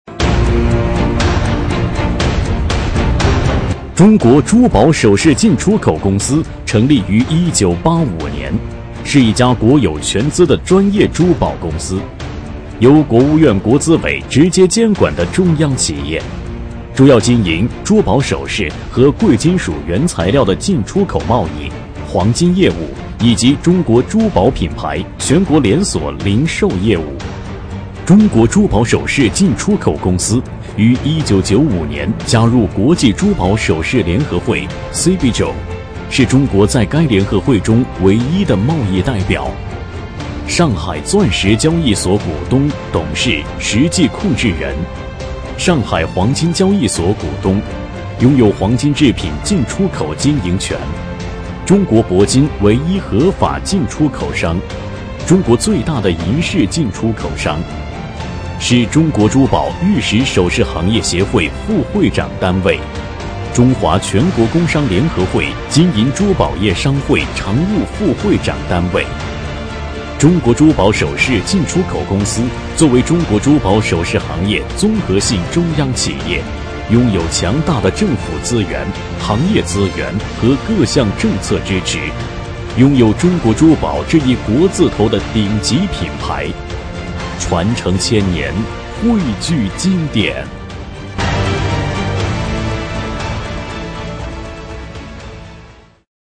专题男配
【男19号宣传片】中国珠宝首饰